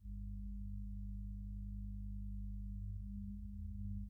Reste groupe électro
electro_seul.wav